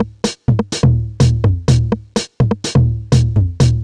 cch_jack_percussion_loop_sizzler_125.wav